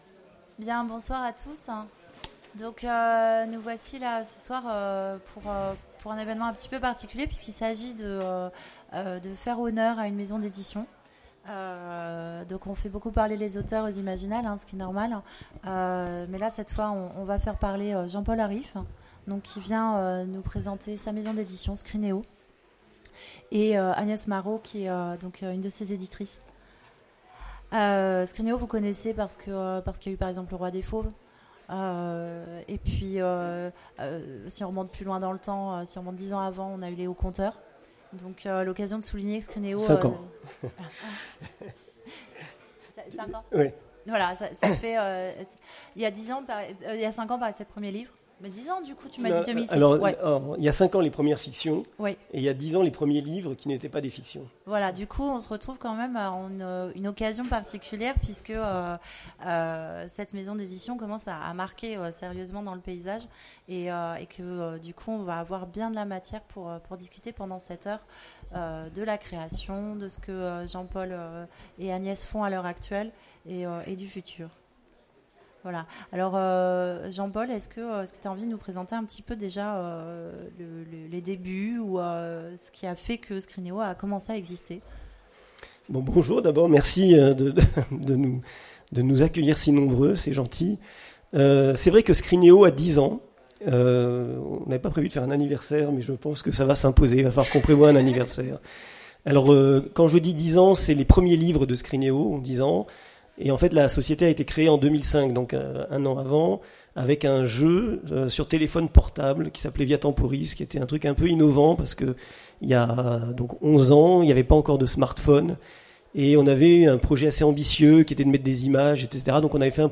Imaginales 2016 : Conférence Scrineo, comment ça marche ?
Mots-clés Rencontre avec une maison d'édition Conférence Partager cet article